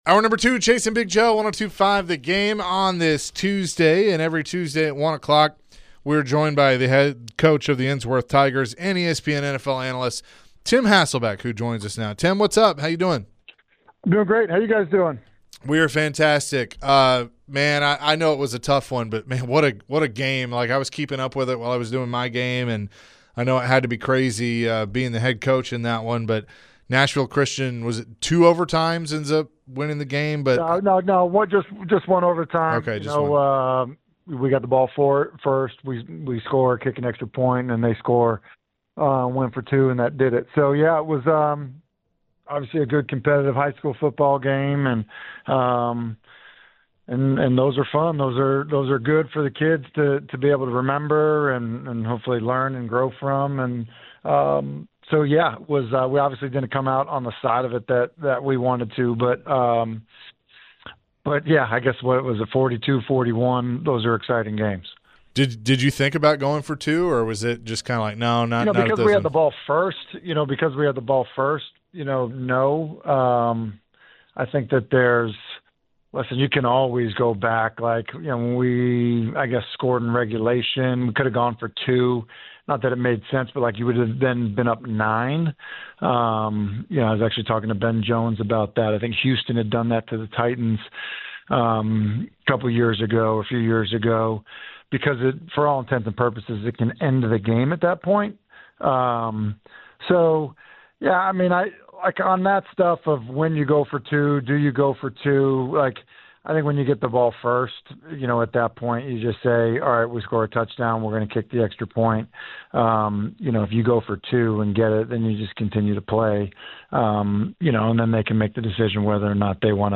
The guys chatted with ESPN NFL Analyst and Ensworth HC Tim Hasselbeck! Tim spoke on his team's loss on Friday and the Tennessee Titans.